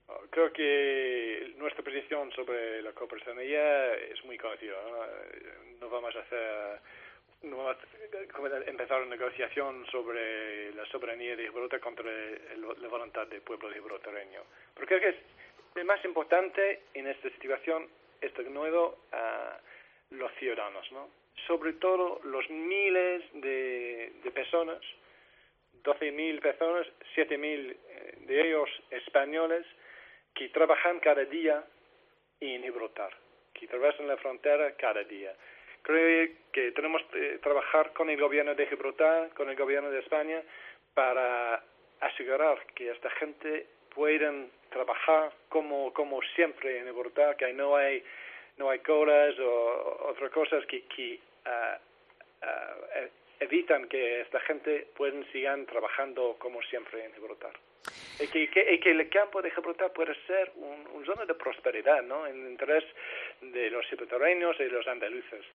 • Escucha la entrevista al embajador de Reino Unido en España, Simon Manley, en 'Fin de Semana'